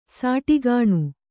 ♪ sāṭigāṇu